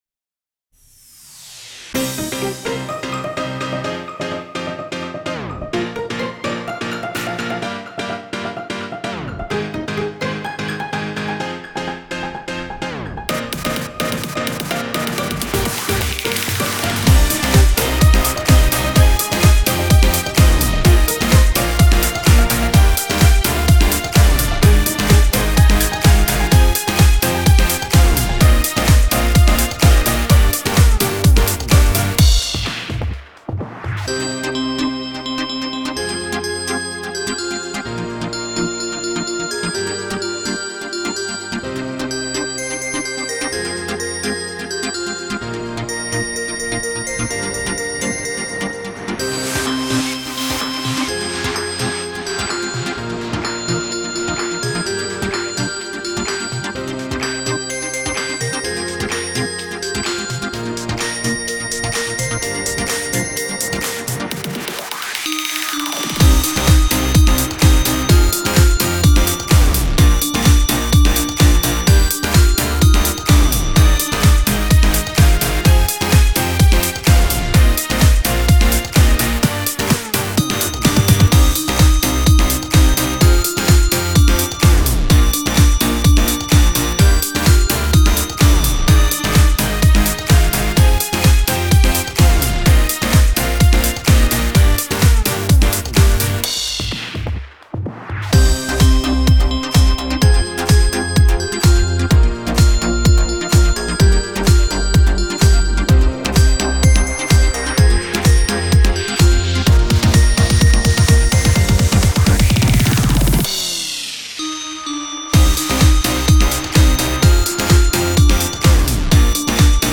Слушать минус